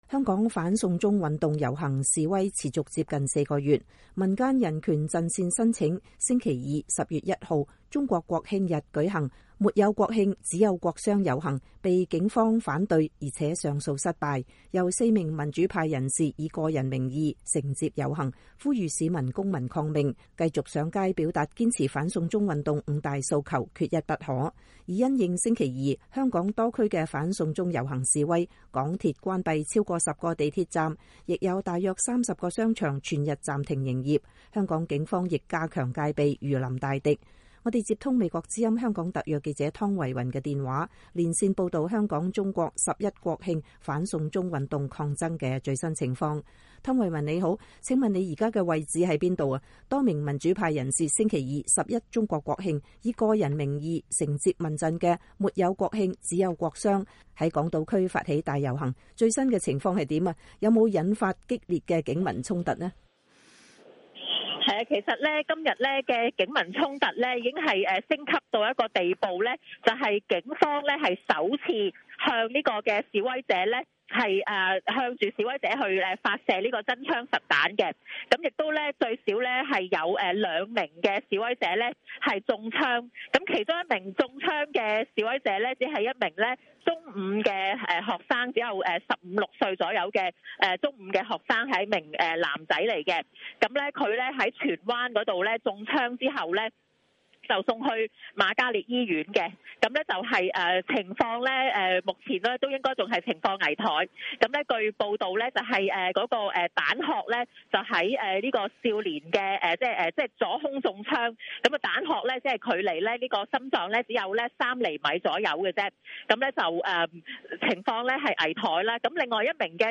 香港十一中國國慶日 反送中全民黑衫抗爭現場報導